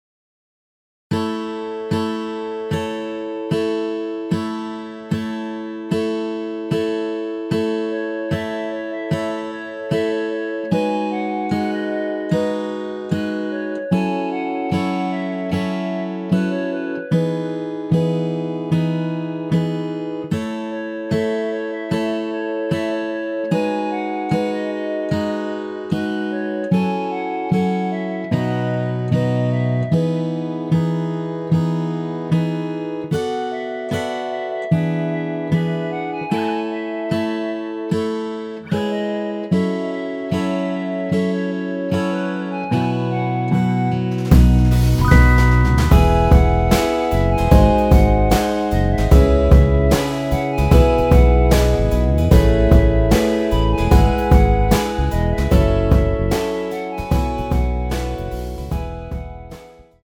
원키에서(+6)올린 멜로디 포함된 MR입니다.(미리듣기 확인)
앨범 | O.S.T
앞부분30초, 뒷부분30초씩 편집해서 올려 드리고 있습니다.
중간에 음이 끈어지고 다시 나오는 이유는